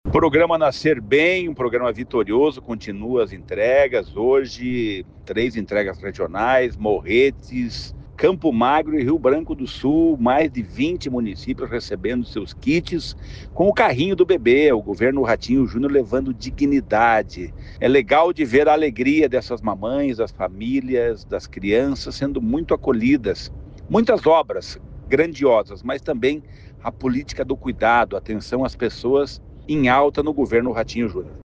Sonora do secretário do Desenvolvimento Social e Família, Rogério Carboni, sobre o programa Nascer Bem Paraná | Governo do Estado do Paraná